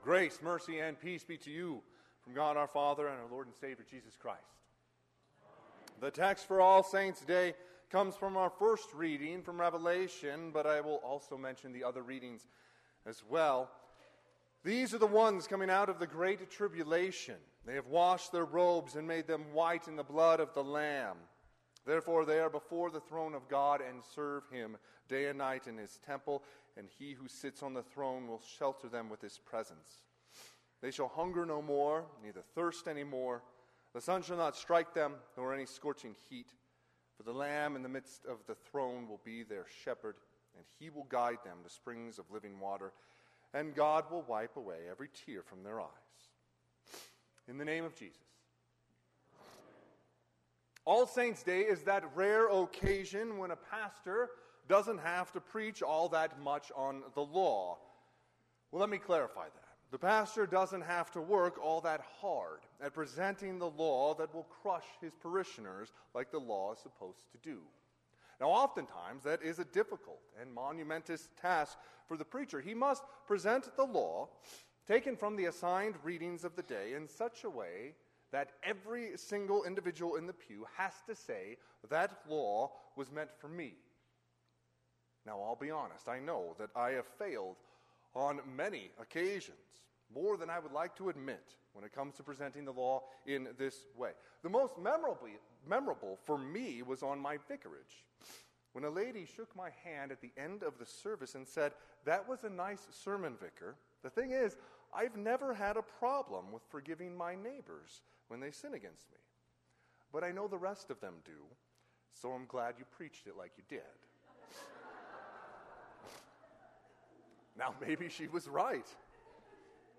Sermon - 11/5/2023 - Wheat Ridge Lutheran Church, Wheat Ridge, Colorado